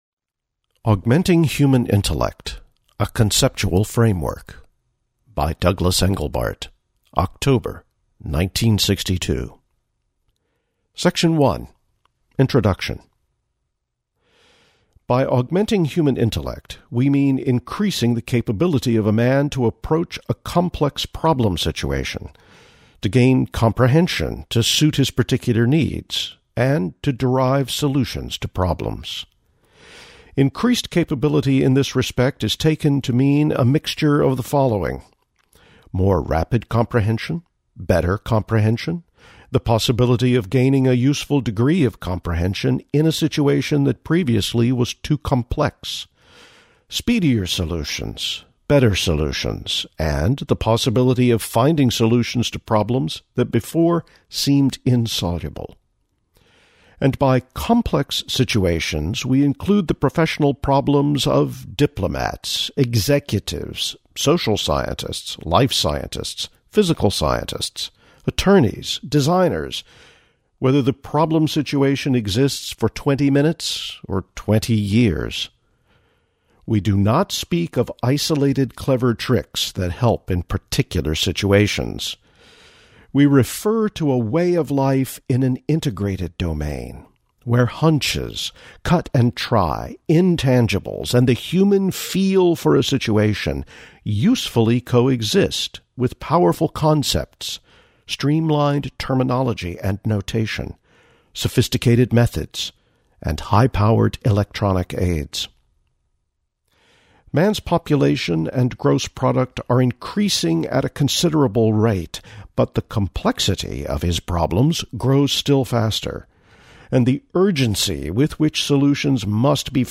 Framework_Annotation_Project_week_1_reading.mp3